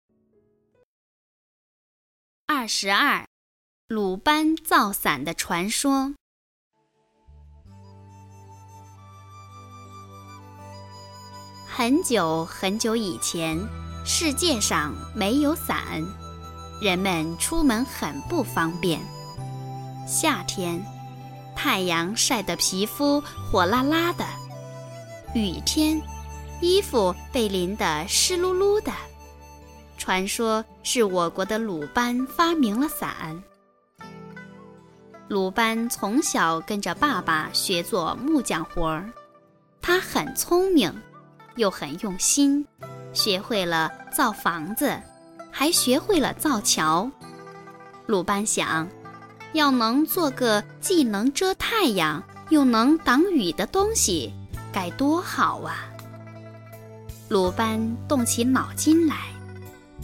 语文三年级上西师版22《鲁班造伞的传说》课文朗读_21世纪教育网-二一教育